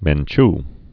(mĕn-ch), Rigoberta Born 1959.